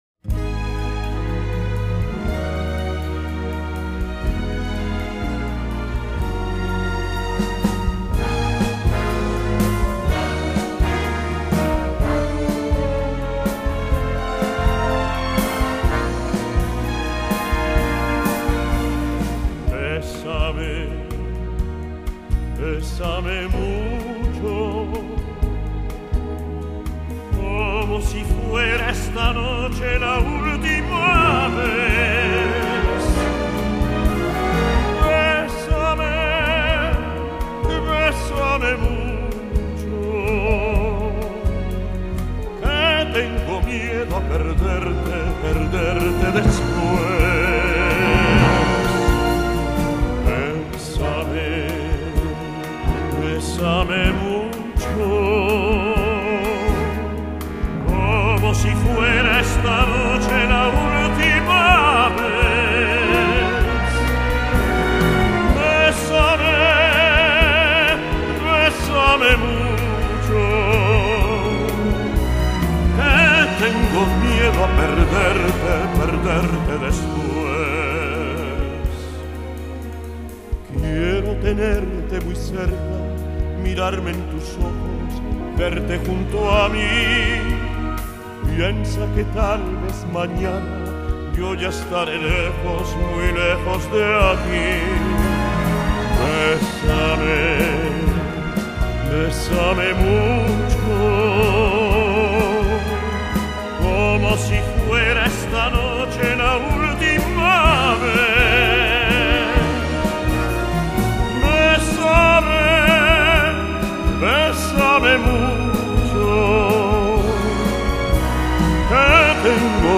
Genre: Classical Crossover
Tenor